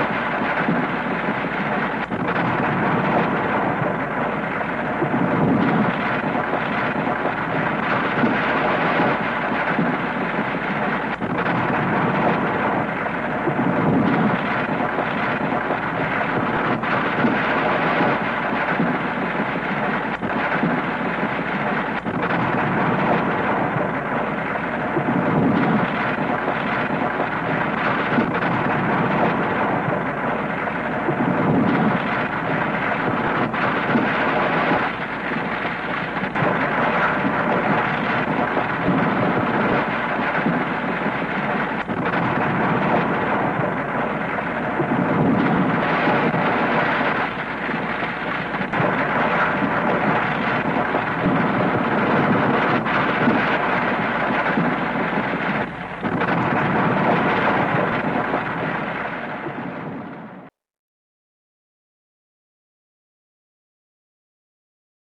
地震効果音約 60秒（WAV形式 約11MB）
地震効果音はフリー音源を上記の秒数に編集したものです。